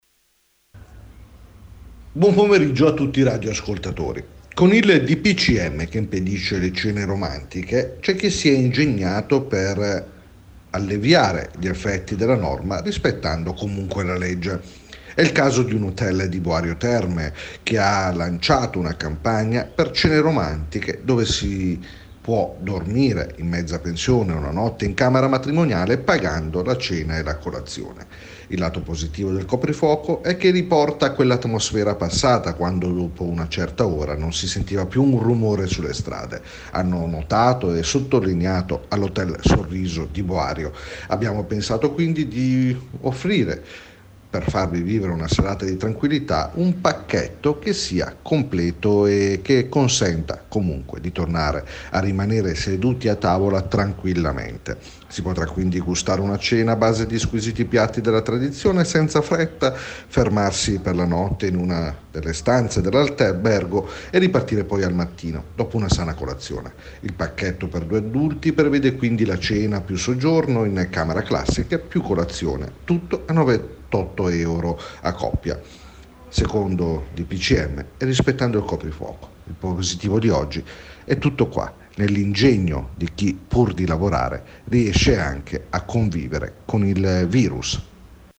RadioGiornale Sera RADIOGIORNALI